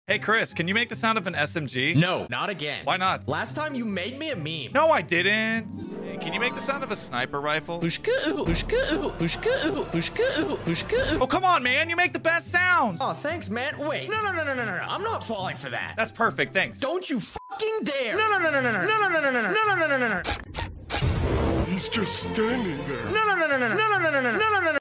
New Gun Noises For SMG’s Sound Effects Free Download